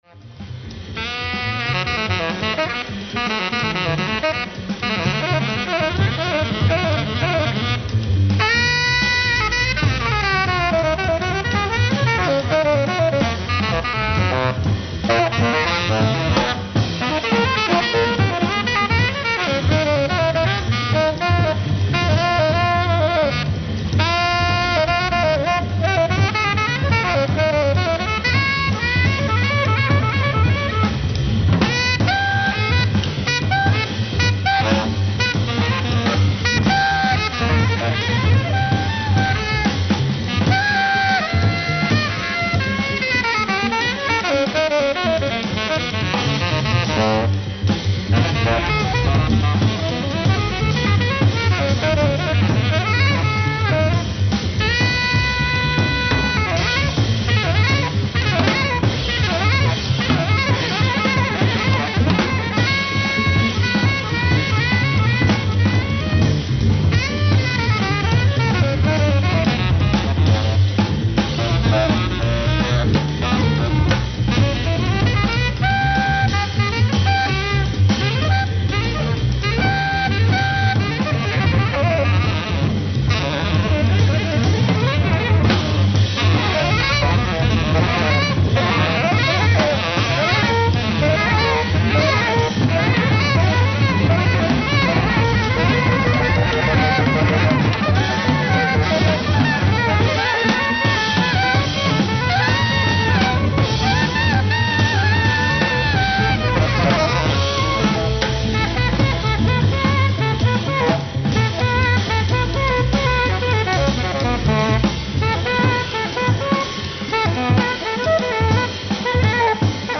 ライブ・アット・ジャズセッション、フランス 05/29/1973
※試聴用に実際より音質を落としています。